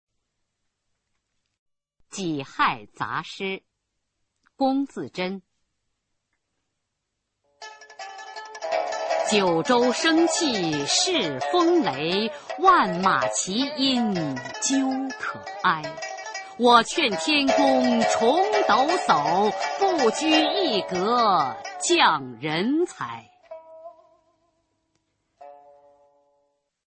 [清代诗词诵读]龚自珍-己亥杂诗（九州）(女) 配乐诗朗诵